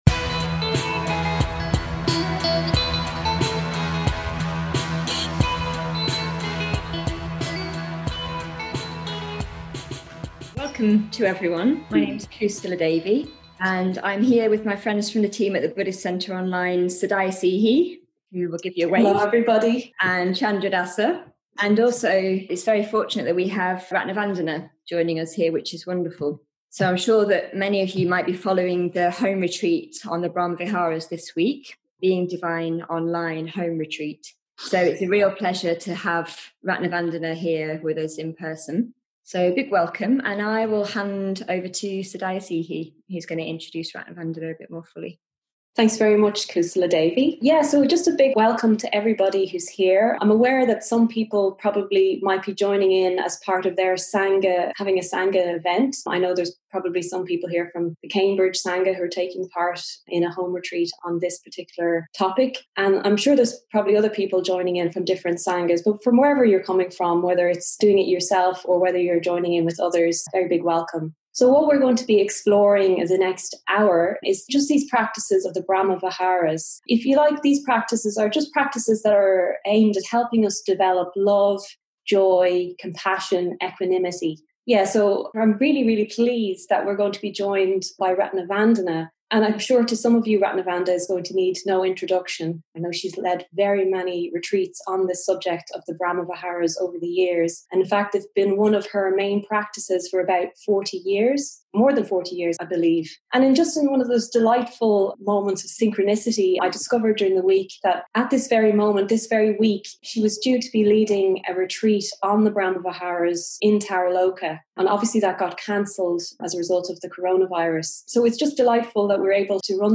Q & A
recorded live online